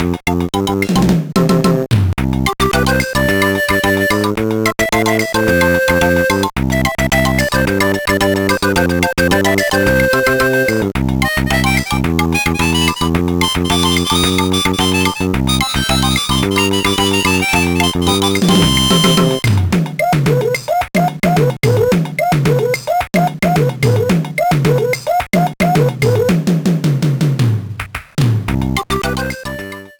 Key C major